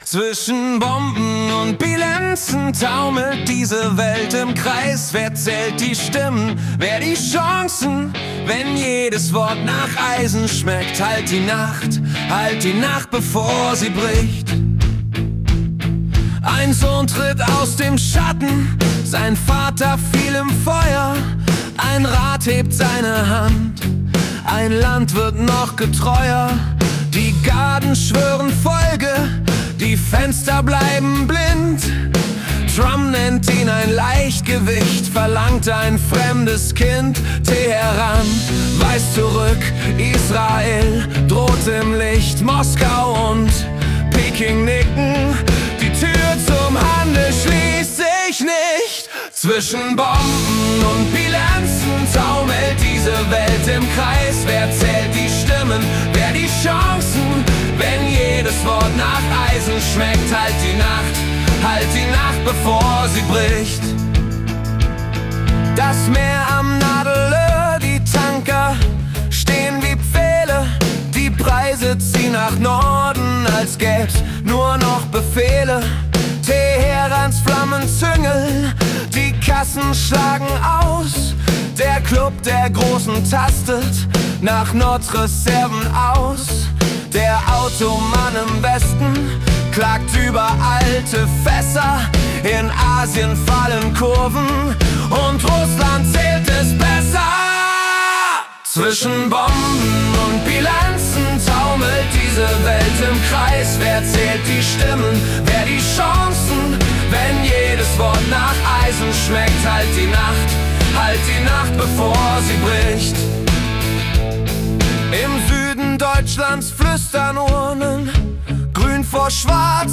März 2026 als Singer-Songwriter-Song interpretiert.